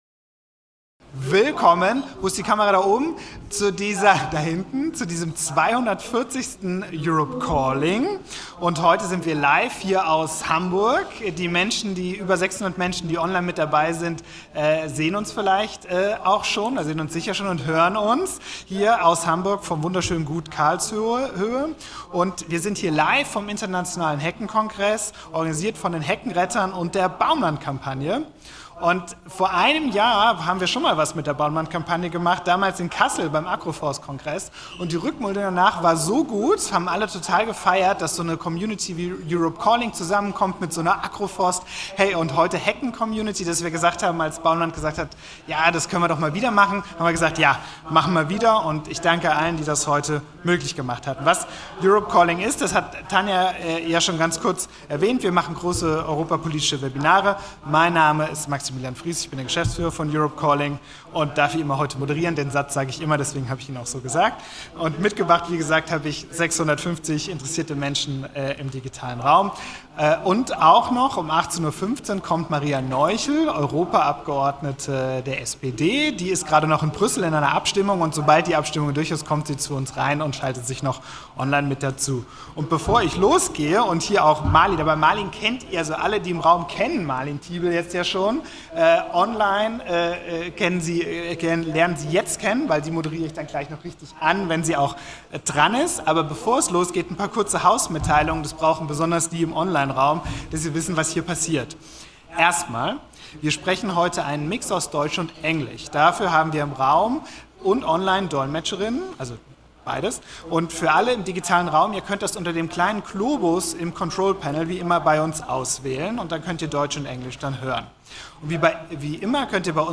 Am 13.10.2025 in Kooperation mit der BaumLand-Kampagne und den Heckenrettern live vom Internationalen Heckenkongress in Hamburg.